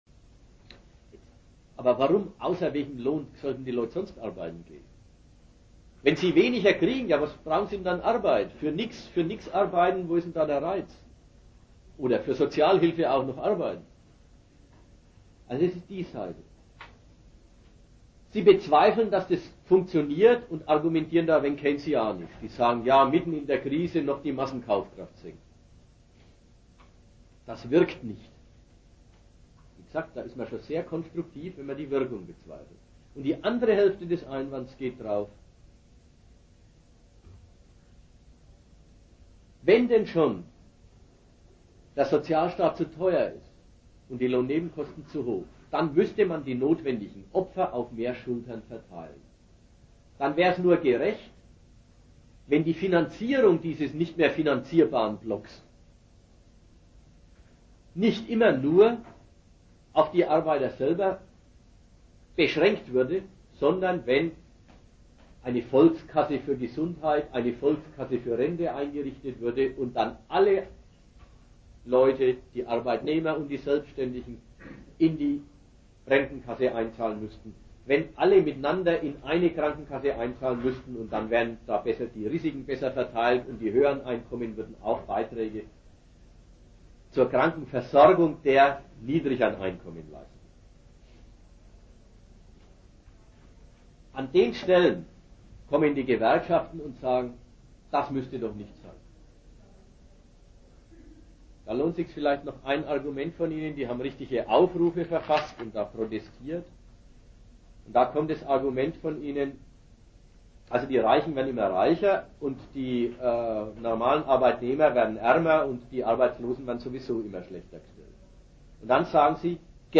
Ort Nürnberg
Dozent Gastreferenten der Zeitschrift GegenStandpunkt